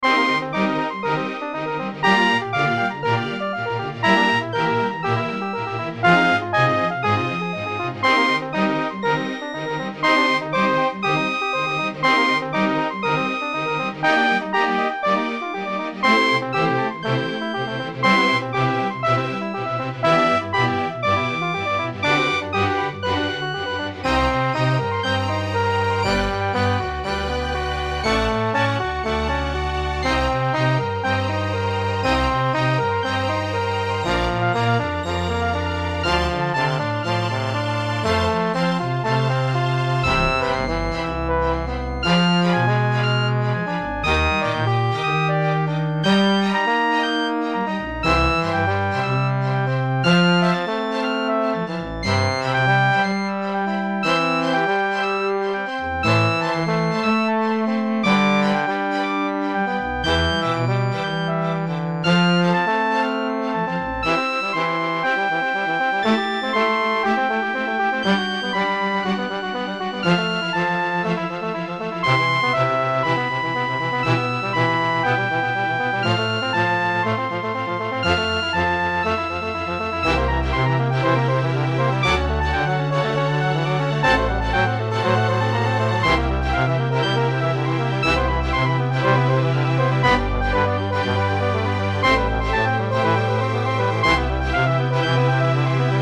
BGM
トロンボーン、コントラバス、ストリング アンサンブル1